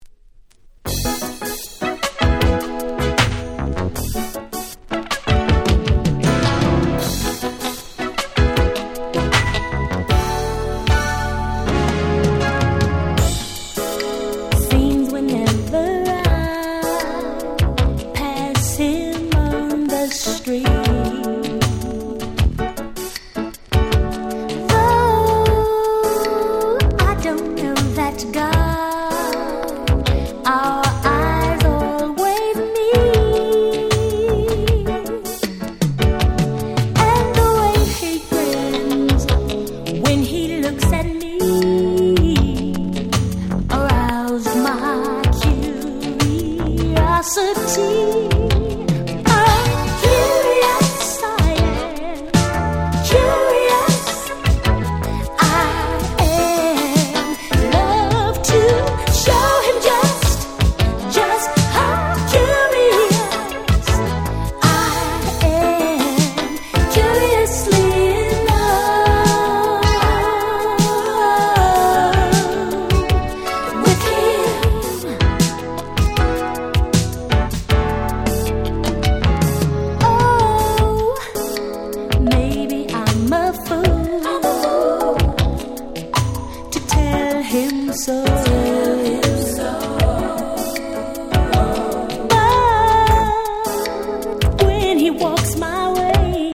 最高のMellow Soul